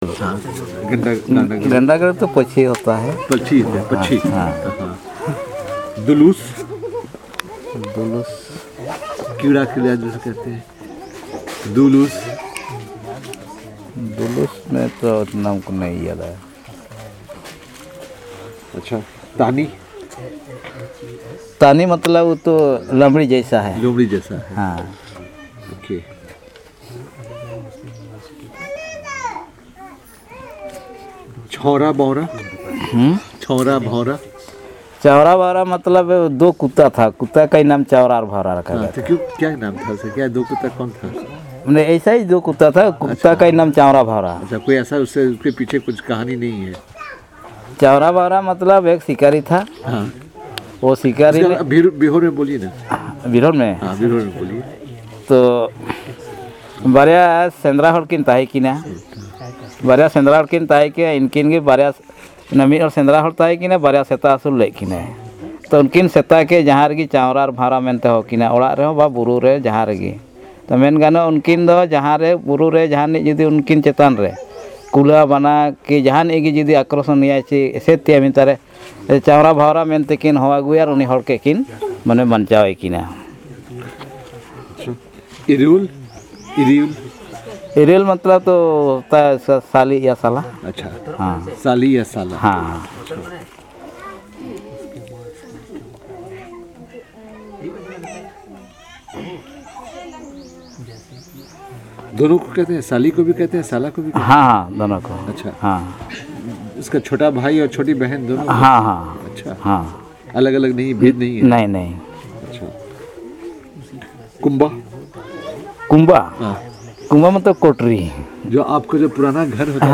Conversation on the meanings of different words and stories